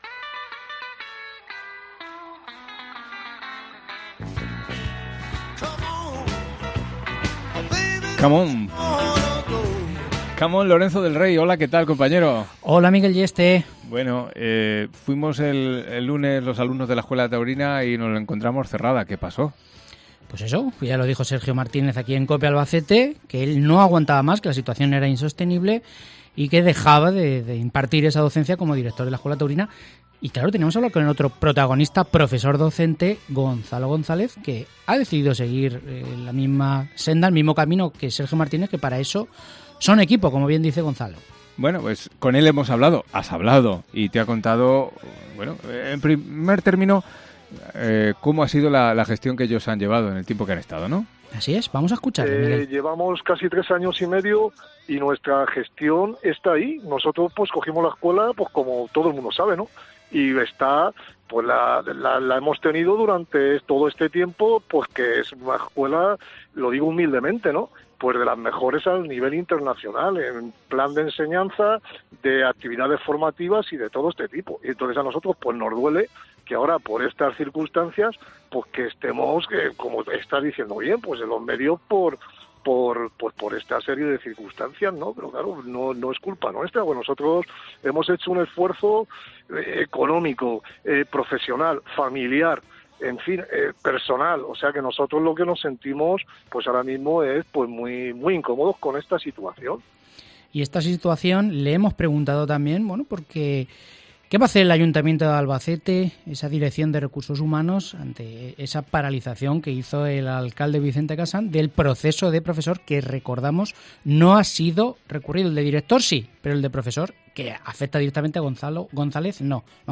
INFORMACIÓN TAURINA